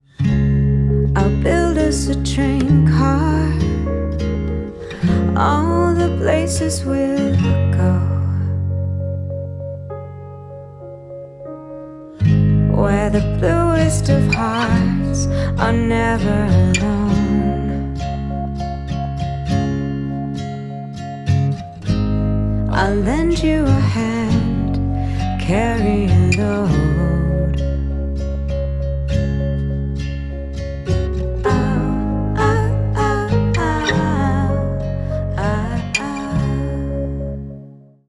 Indie/Acoustic unpolished